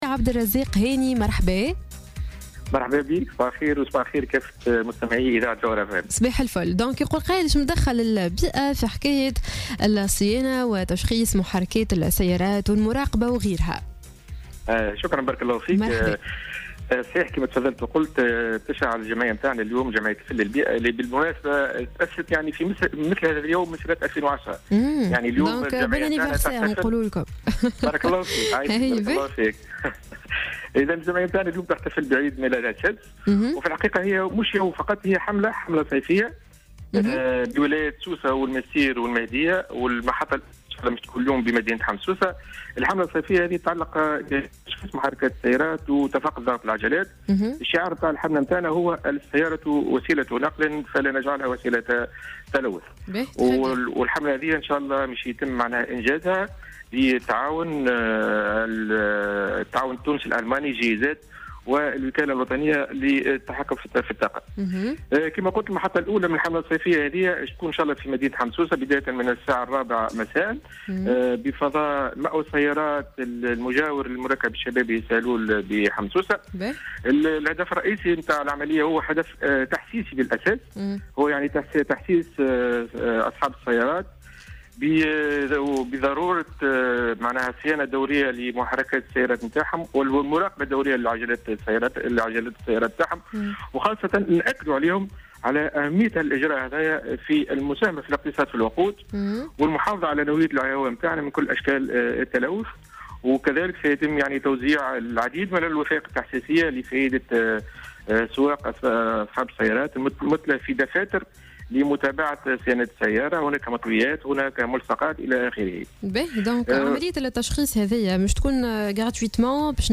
مداخلة